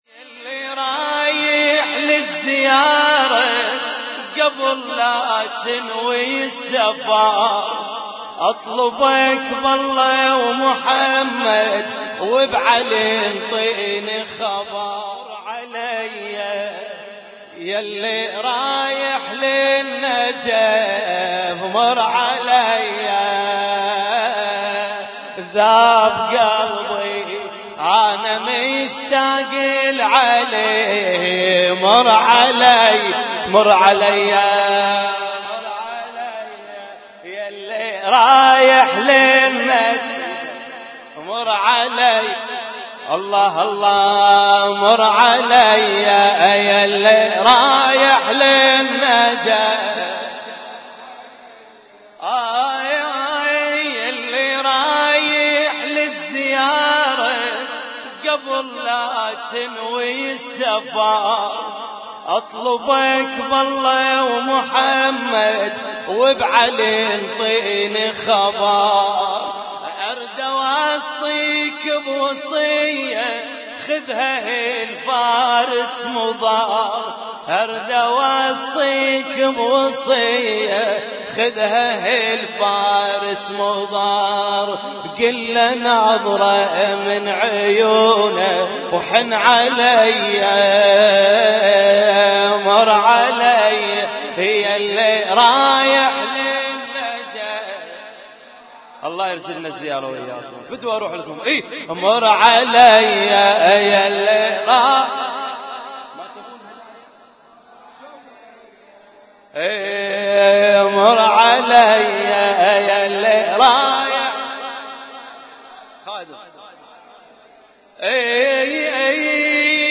اسم التصنيف: المـكتبة الصــوتيه >> المواليد >> المواليد 1437